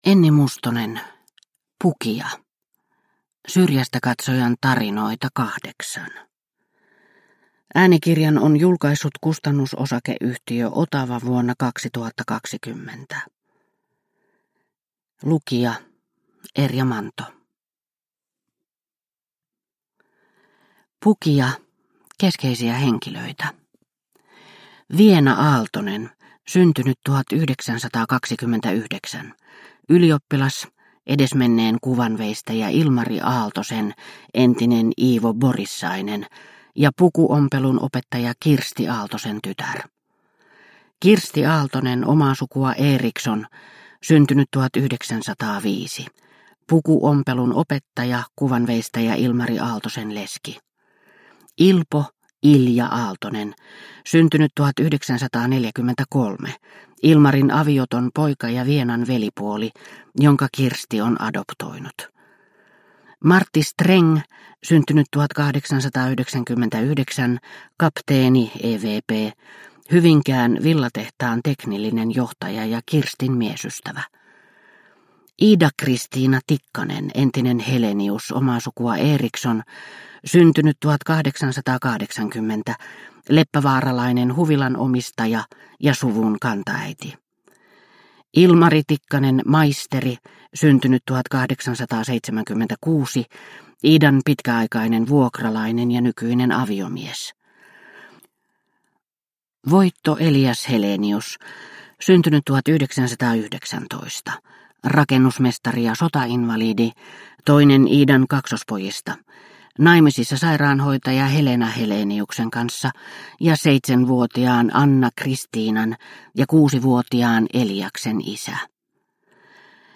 Pukija – Ljudbok – Laddas ner